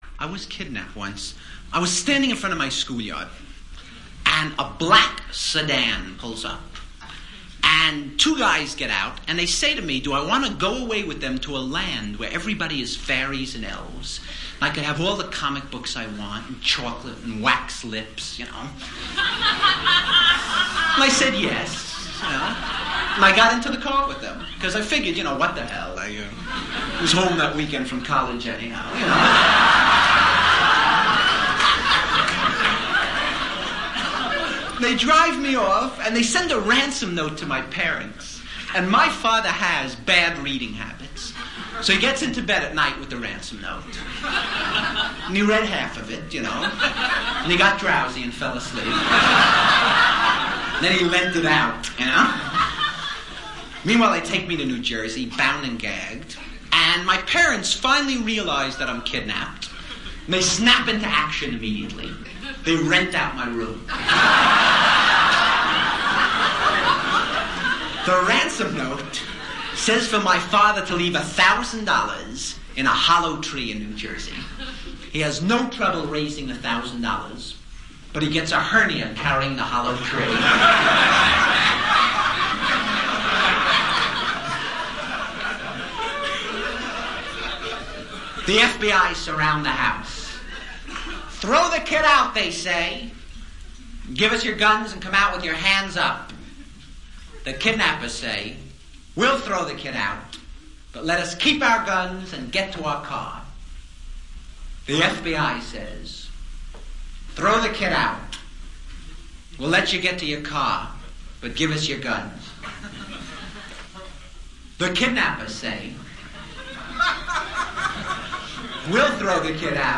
几乎5秒一个富有哲思的包袱带你体会美国单口相声的不同之处。